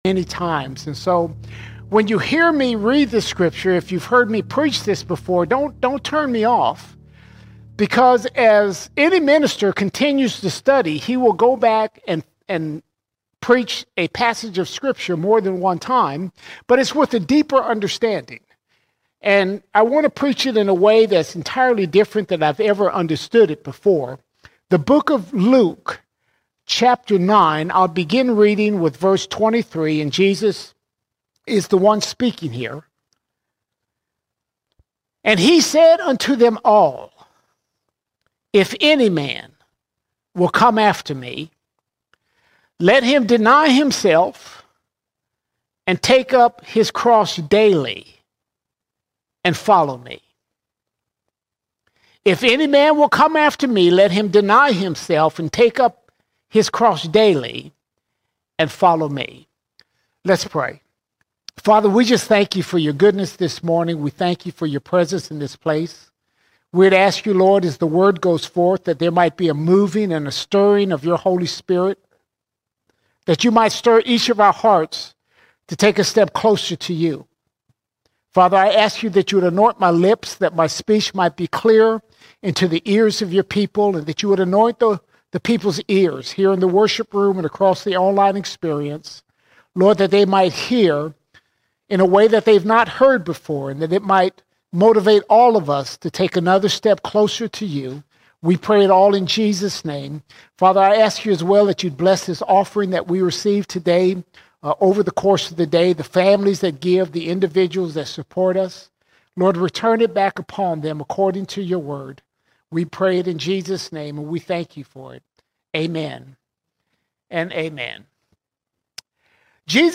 24 February 2025 Series: Sunday Sermons All Sermons The Disciple The Disciple A Christian is more than just a believer—we are called to be disciples, daily denying ourselves, taking up our cross, and following Him.